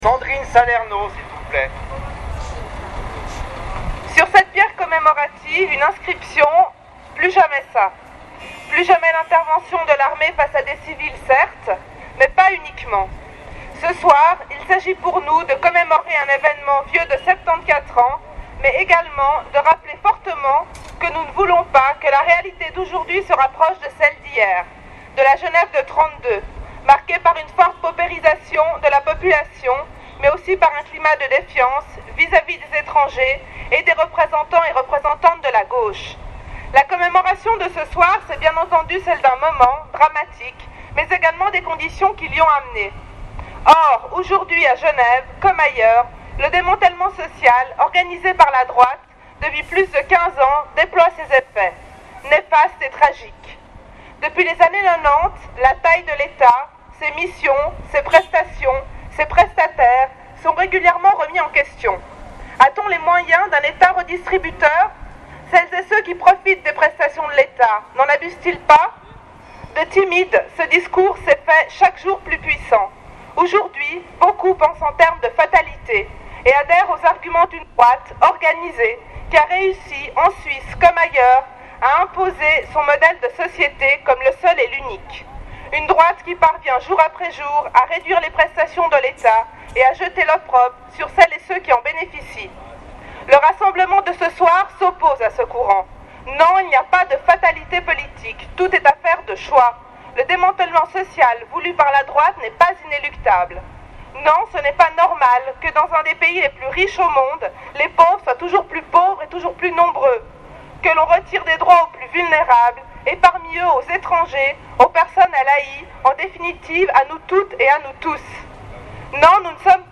discours de Sandrine Salerno
sandrine-salerno-mono.mp3